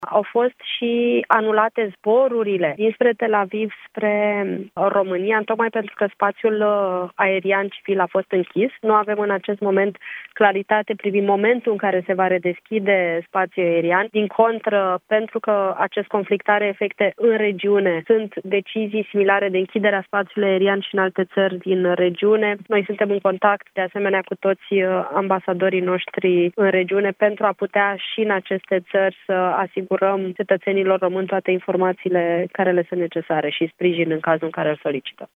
Ministra Afacerilor Externe, Oana Țoiu, la Europa FM: